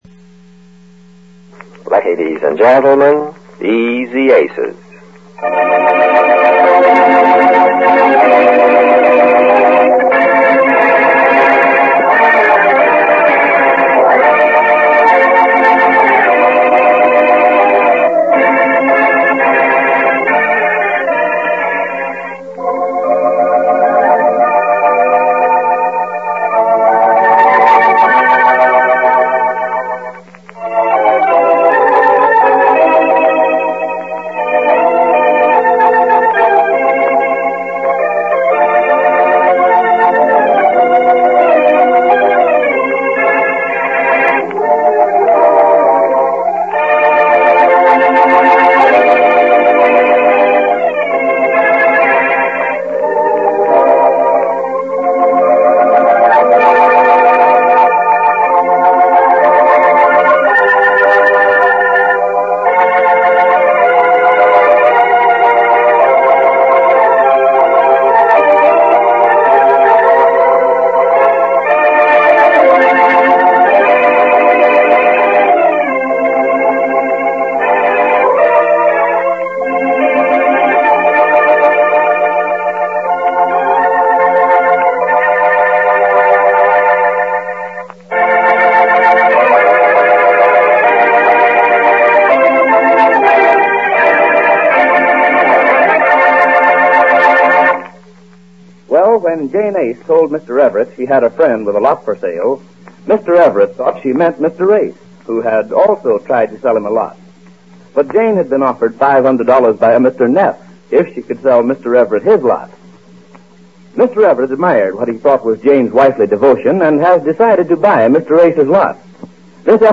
Easy Aces Radio Program, Starring Goodman Aiskowitz and Jane Epstein-Aiskowitz